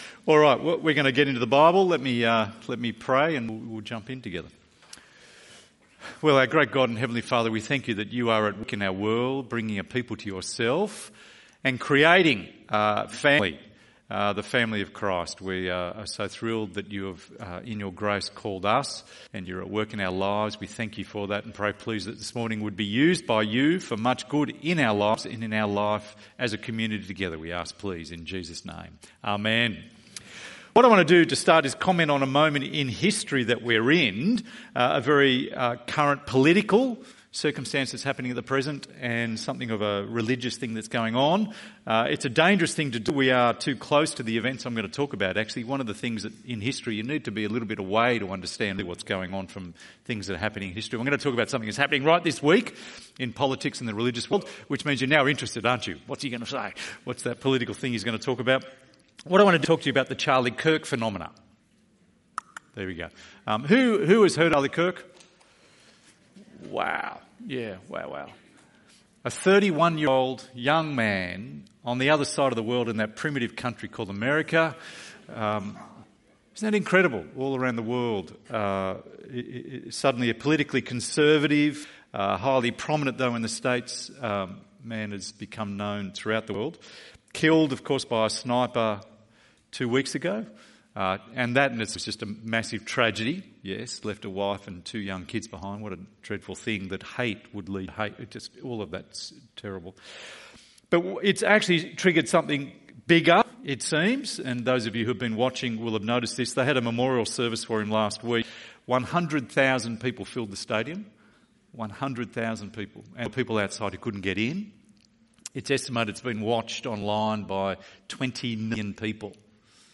The Lord who shows mercy ~ EV Church Sermons Podcast